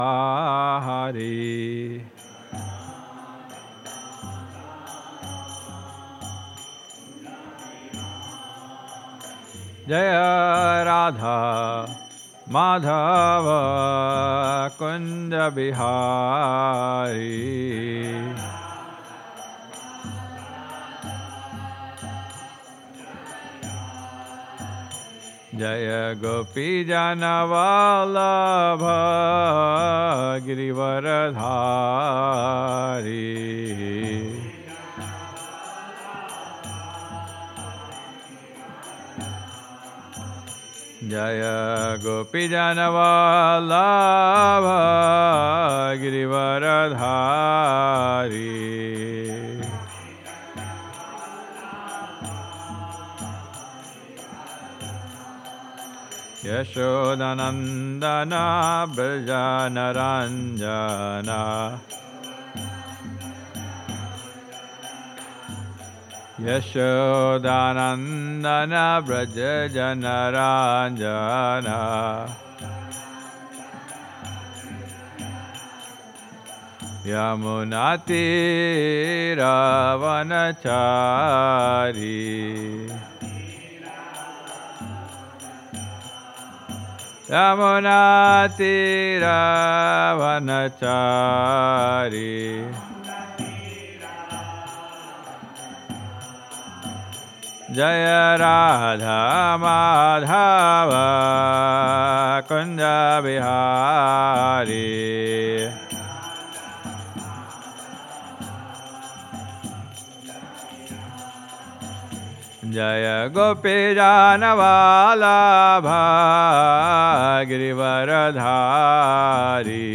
at the Hare Krishna temple in Alachua, Florida